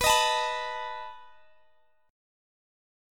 B7 Chord (page 4)
Listen to B7 strummed